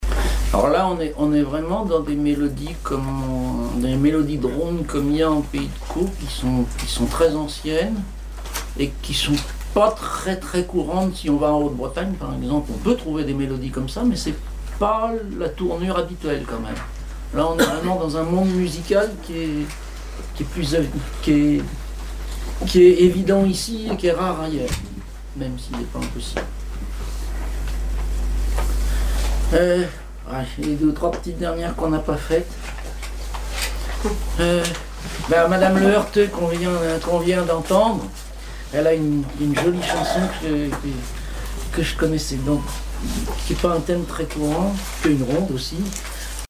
chanteur(s), chant, chanson, chansonnette
Chansons et commentaires
Catégorie Témoignage